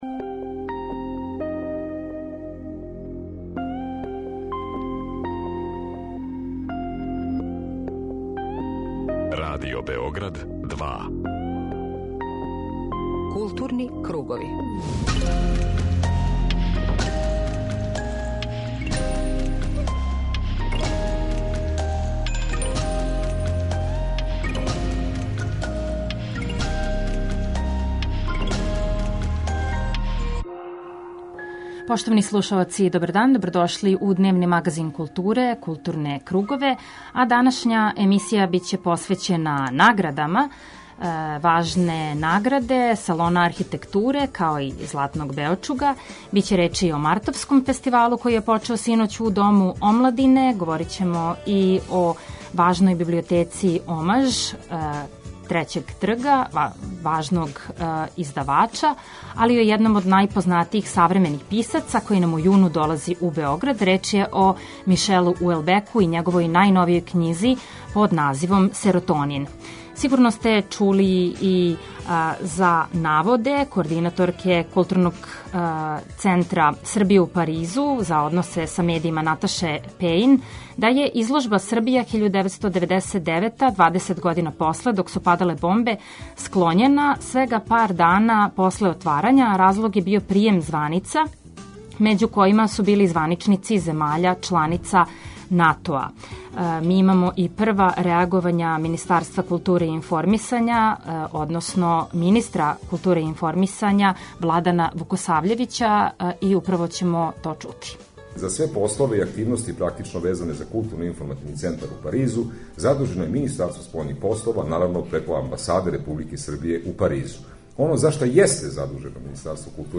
Дневни магазин културе Радио Београда 2